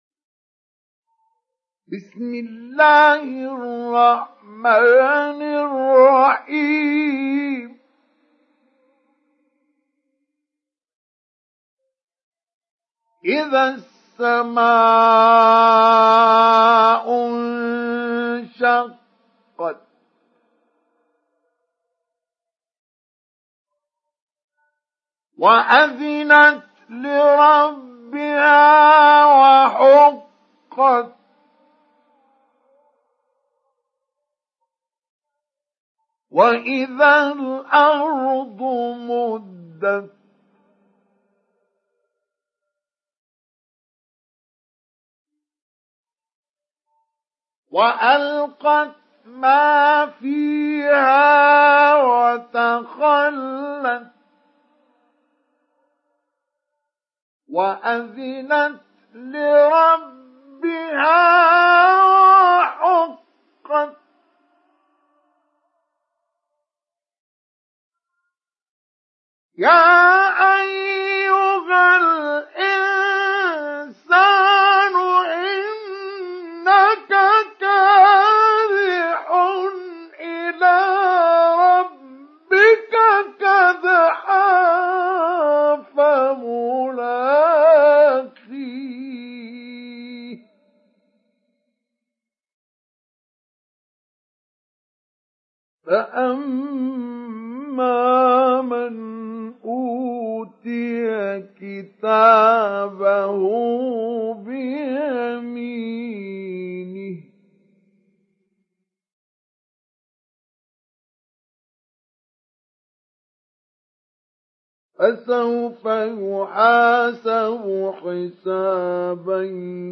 تحميل سورة الانشقاق mp3 بصوت مصطفى إسماعيل مجود برواية حفص عن عاصم, تحميل استماع القرآن الكريم على الجوال mp3 كاملا بروابط مباشرة وسريعة
تحميل سورة الانشقاق مصطفى إسماعيل مجود